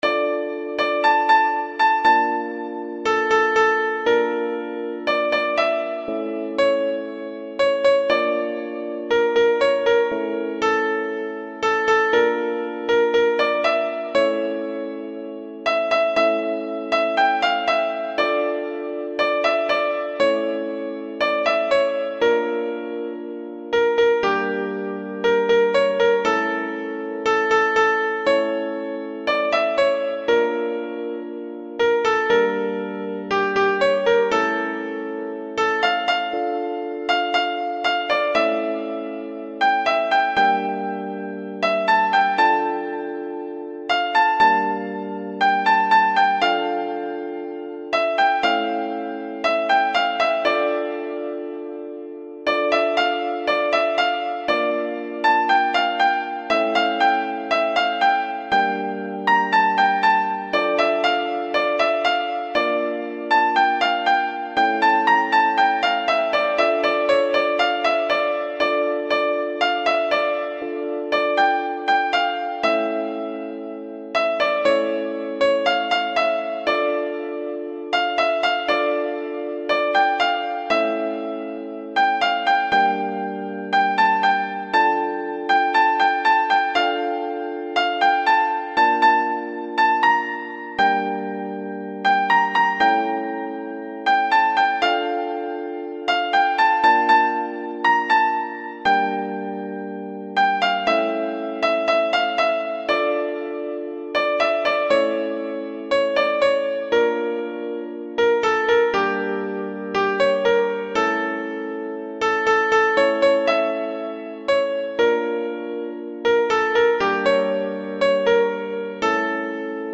ساز : کیبورد